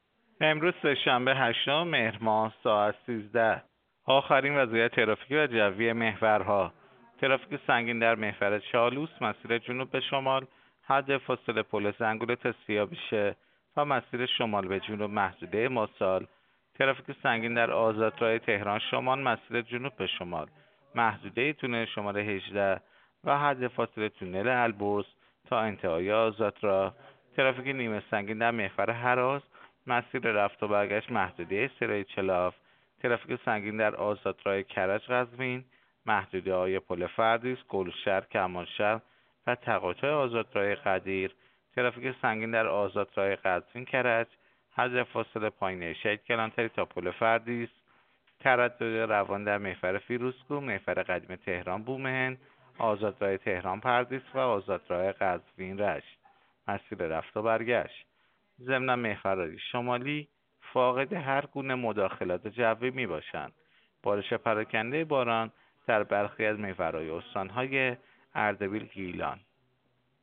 گزارش رادیو اینترنتی از آخرین وضعیت ترافیکی جاده‌ها ساعت ۱۳ هشتم مهر؛